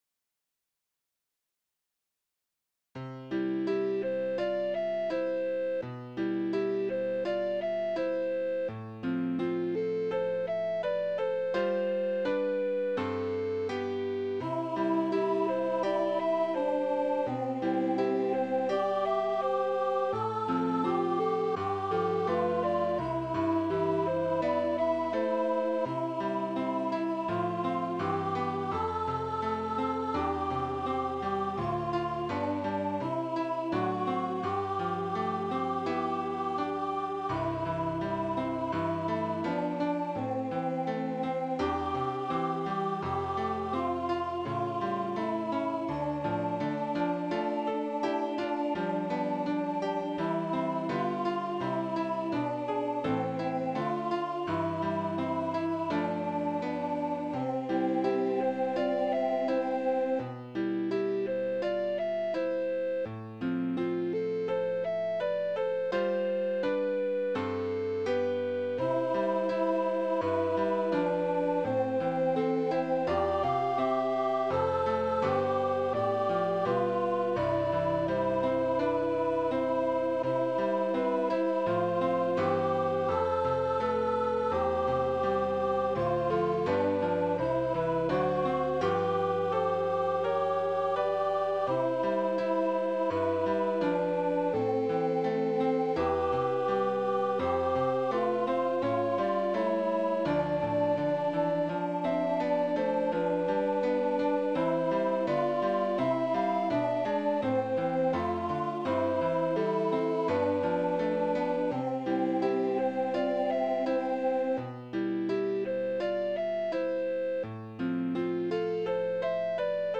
can be sung alone or with a flute/violin obbligato